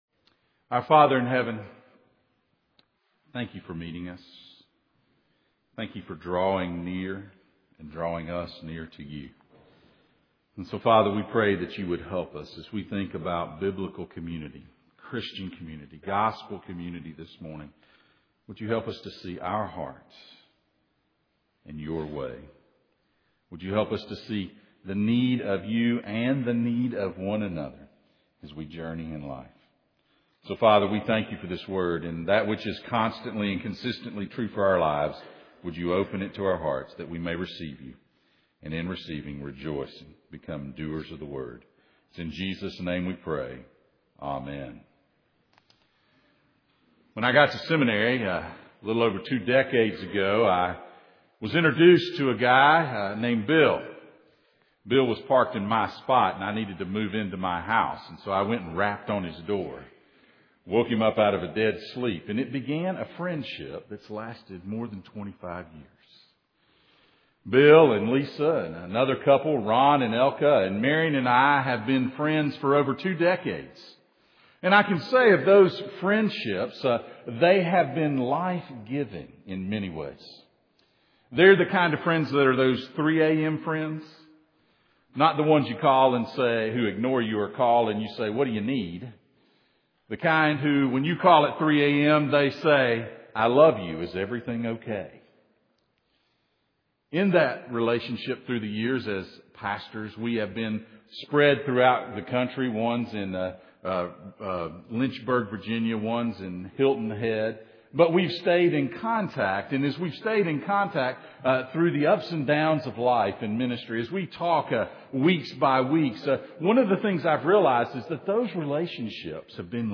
Hebrews 10:19-25 Service Type: Sunday Morning